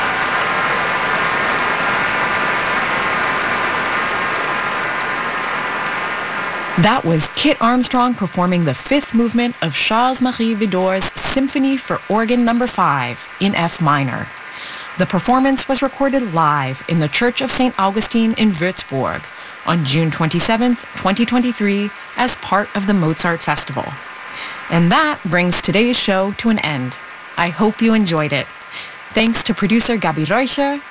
Recording of noticeable CBET tones during applause and commentary. Recorded from WQXR-FMFrequency Modulation.
WQXRCBET.wav